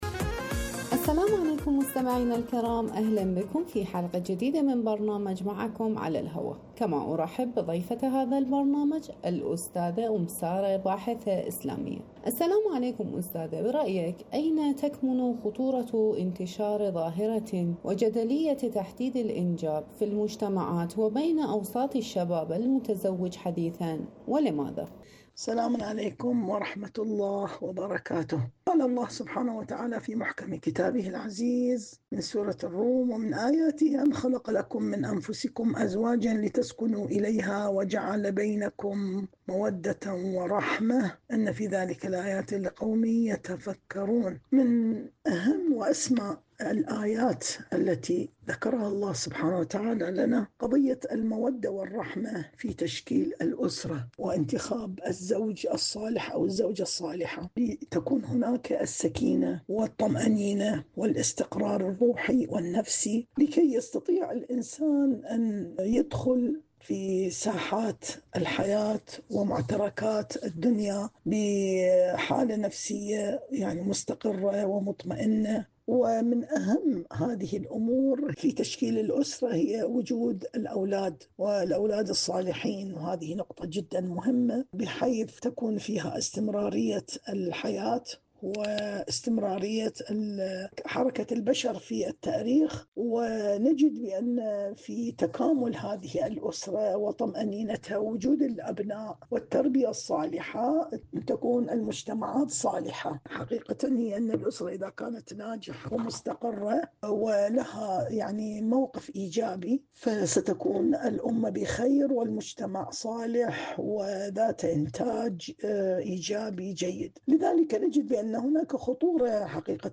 إذاعة طهران-معكم على الهواء: مقابلة إذاعية